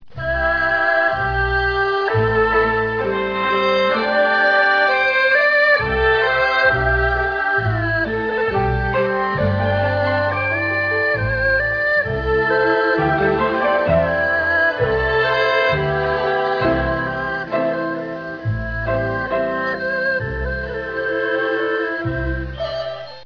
Erhu
With a range of around three octaves, it's sound is rather like the violin, but with a thinner tone due to the smaller resonating chamber.
Erhu clip: an un-sharp violin.
erhu.rm